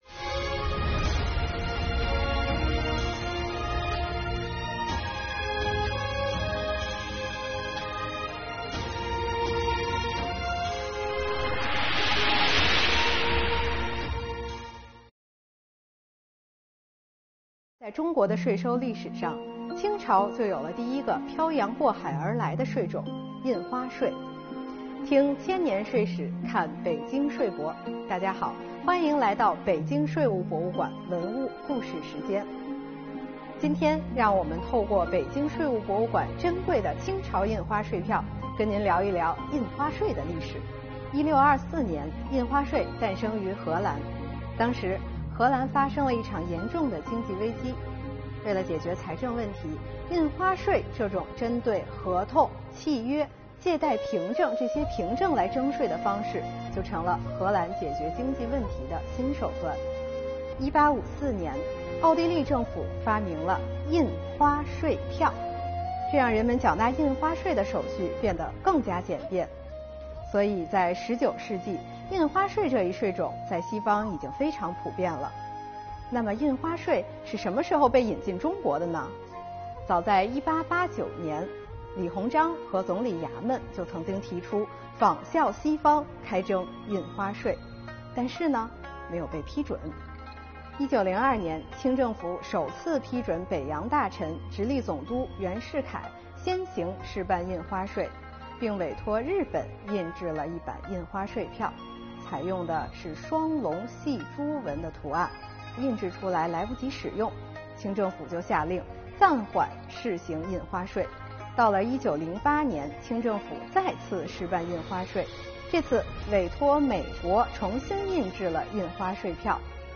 北京税务博物馆文物故事讲述人为大家介绍印花税的历史以及晚清引进印花税的故事↓